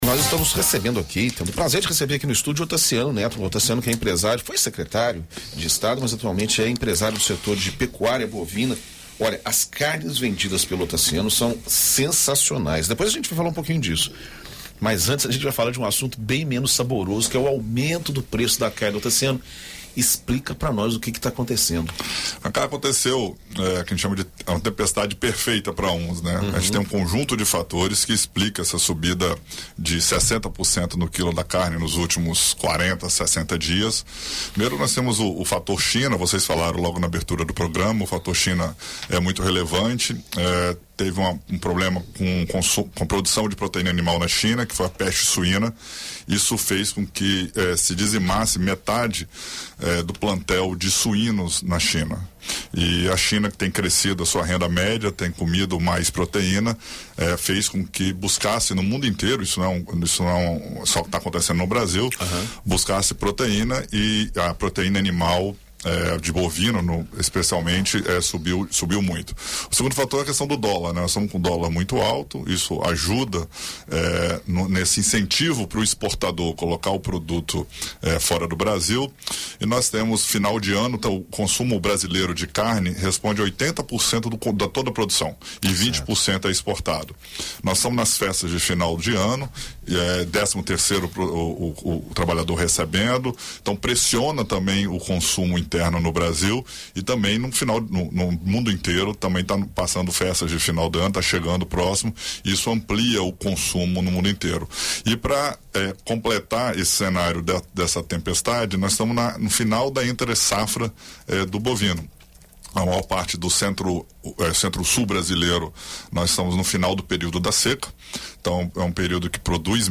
Em entrevista a BandNews FM Espírito Santo